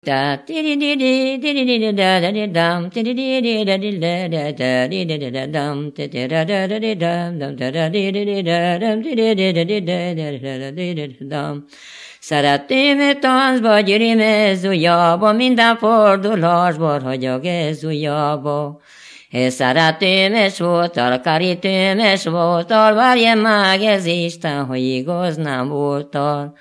Moldva és Bukovina - Moldva - Klézse
ének
Műfaj: Serény magyaros
Stílus: 6. Duda-kanász mulattató stílus
Kadencia: 5 X 5 (b3) X b3 X 1